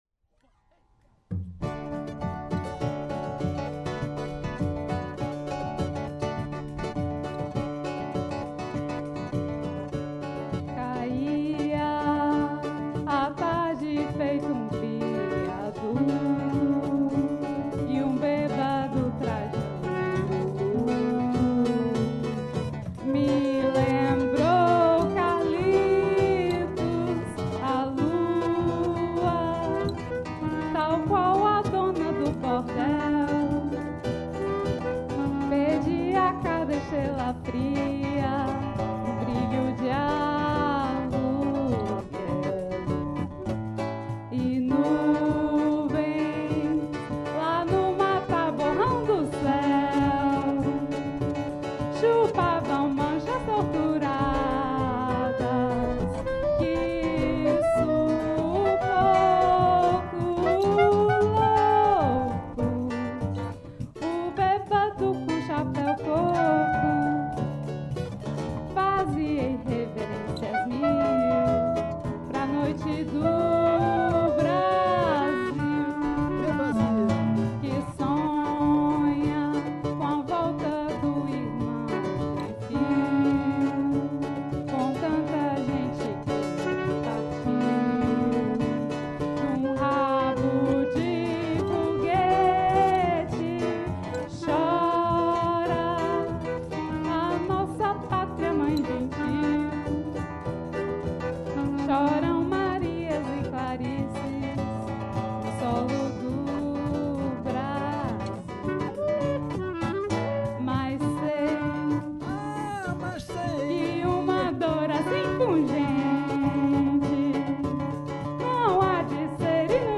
Joué pour la fête de la Musique 2024 au CE TAS